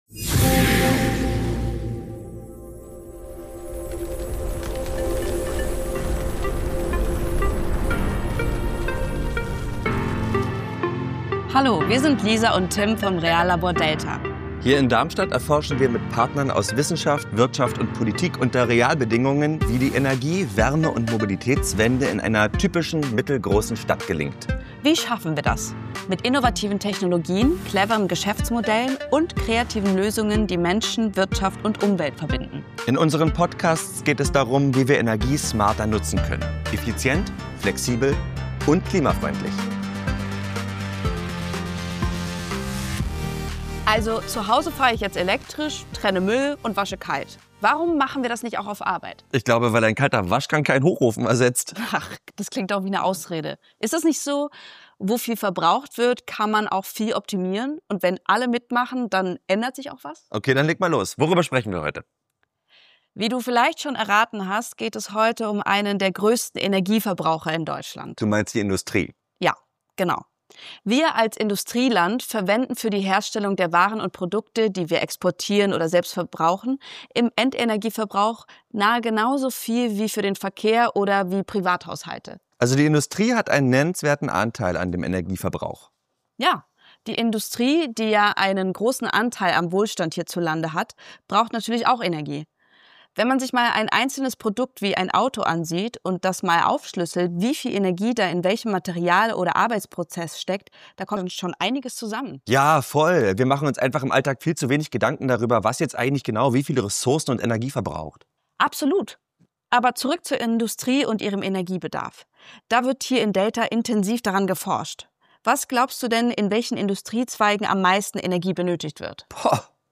Wir zeigen, wie in Fabriken Prozesse so optimiert werden können, dass nicht nur weniger Energie verbraucht, sondern sogar überschüssige Energie in den Kreislauf zurückfließen kann. Gemeinsam mit unseren Interviewpartnern Prof. Dr.-Ing.